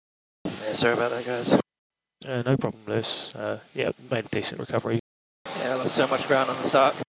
radio message was sent.